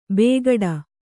♪ bēgaḍa